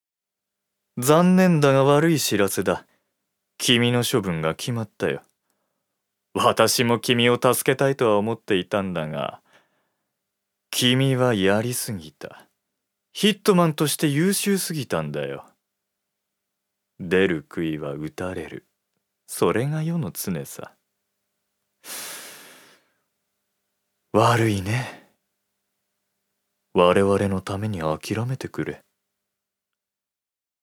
所属：男性タレント
セリフ３